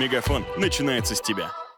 слоган